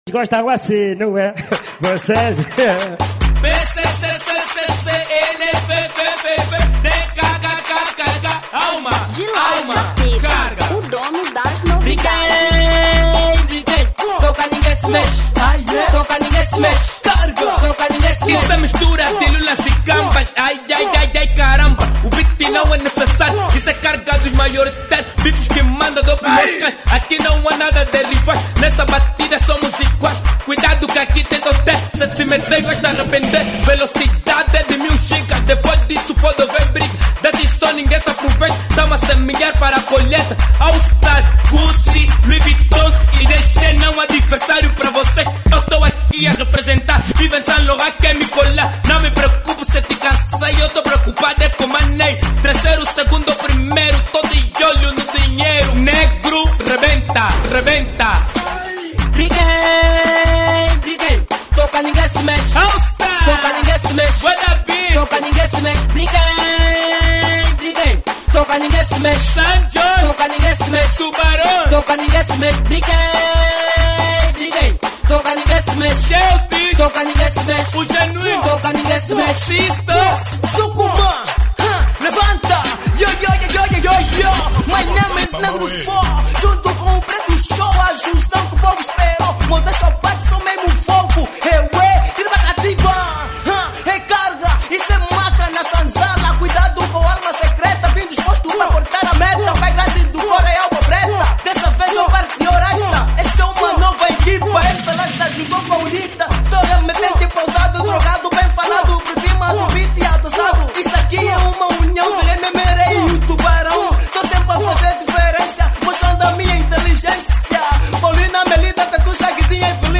Kuduro 2009